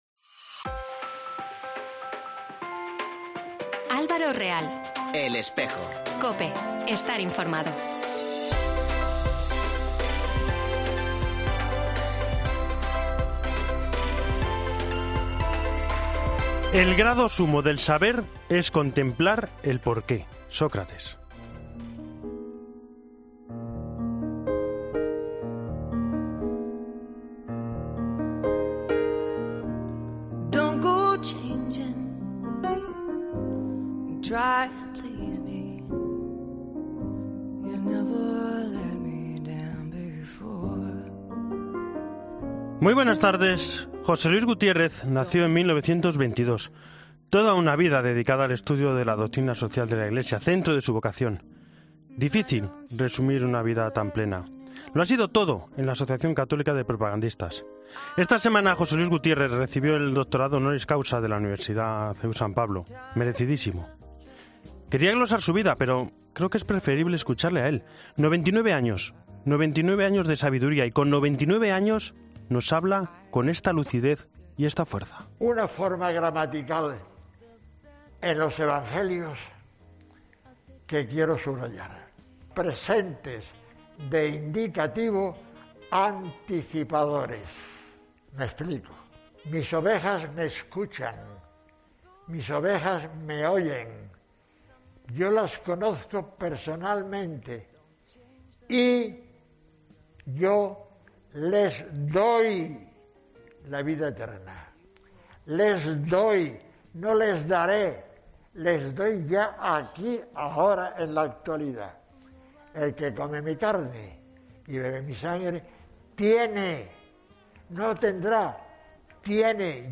En Espejo 30 enero 2021: Coloquo sobre Vida Consagrada